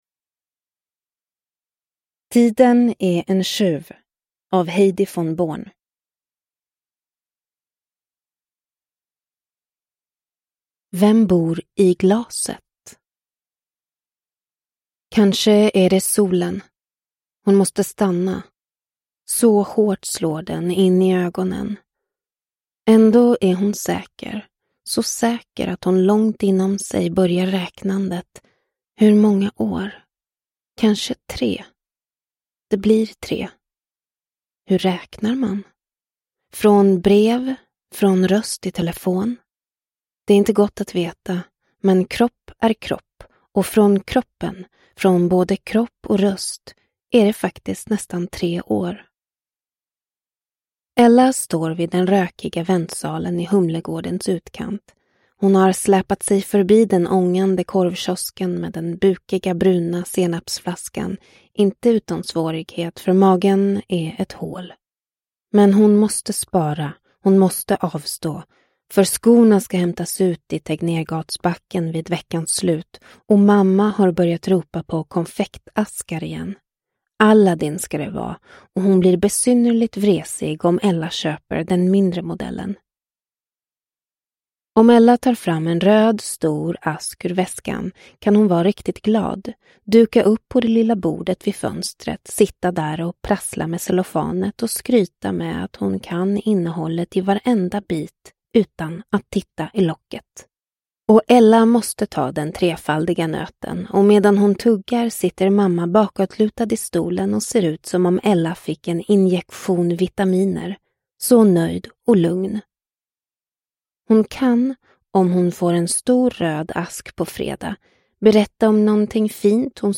Tiden är en tjuv – Ljudbok – Laddas ner